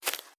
Grass Step 03.wav